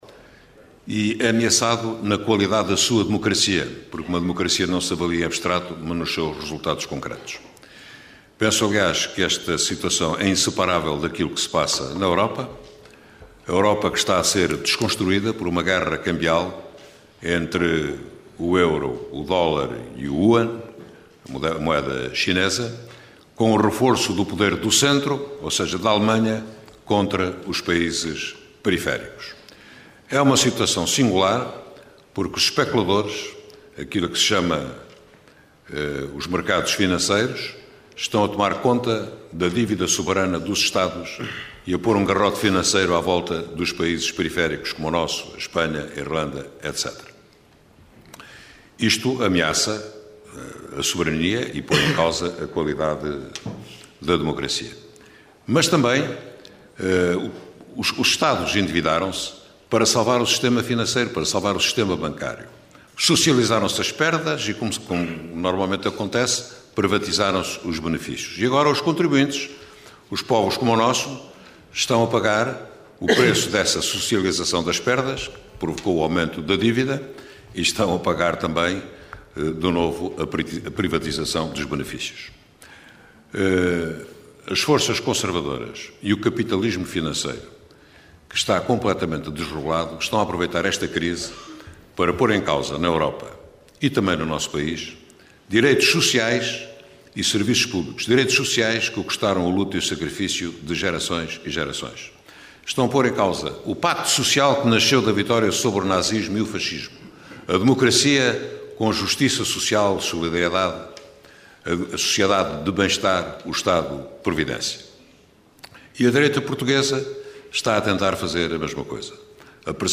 A iniciativa contou com uma plateia recheada de jovens universitários que durante uma hora deram ouvidos a temas como a situação de Portugal e da Europa, o desemprego e a precariedade entre os jovens.